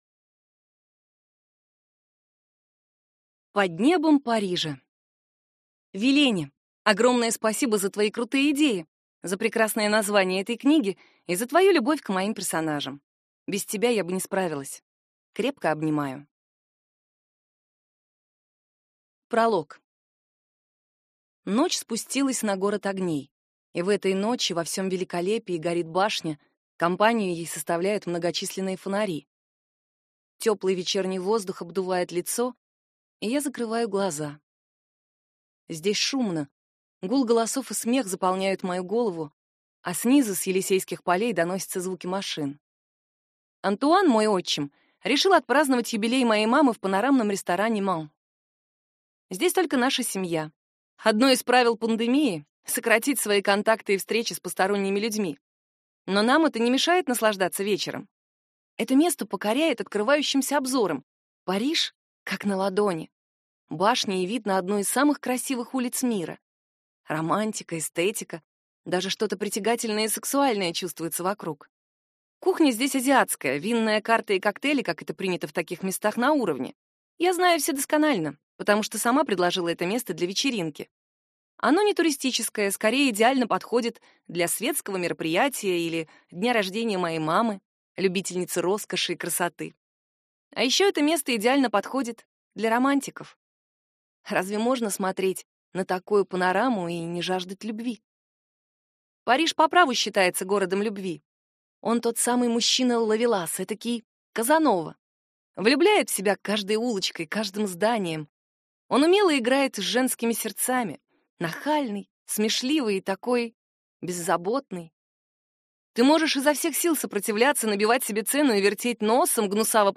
Аудиокнига Под небом Парижа | Библиотека аудиокниг